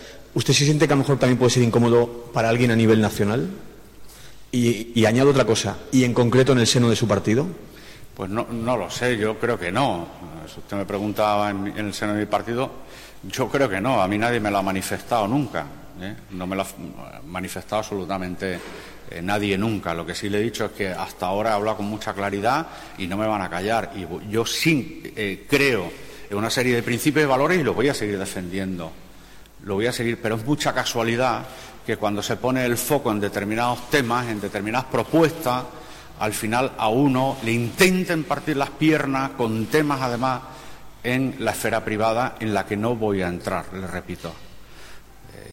Fragmento de la rueda de prensa del Presidente de Extremadura en relación con los 32 viajes en 18 meses que realizó a Canarias cuando era senador